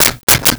Switch 01
Switch 01.wav